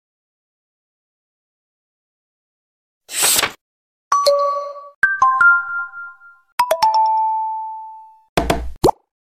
Xiaomi Notification Sound Evolution 2012-2025